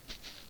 Footstep.ogg